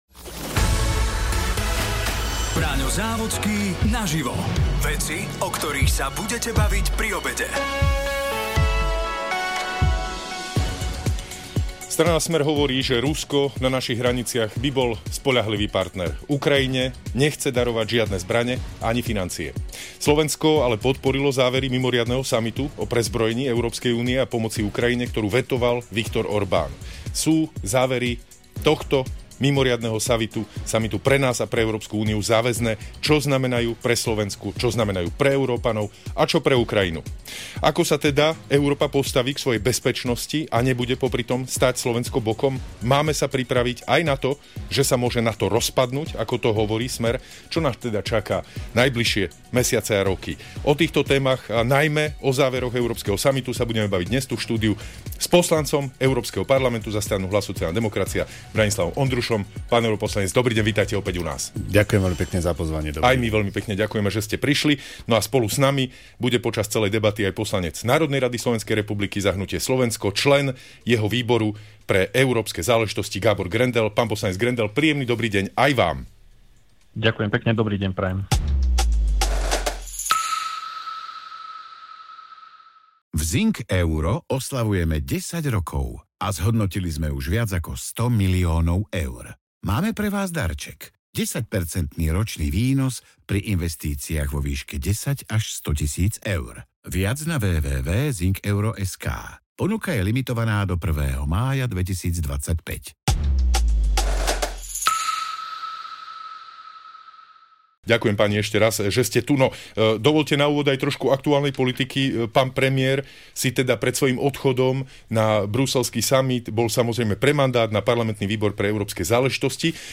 sa rozprával s poslancom Európskeho parlamentu za Hlas – SD Branislavom ONDRUŠOM a poslancom parlamentu za hnutie Slovensko a členom jeho Výboru pre európske záležitosti Gáborom Grendelom.